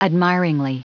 Prononciation du mot admiringly en anglais (fichier audio)
Prononciation du mot : admiringly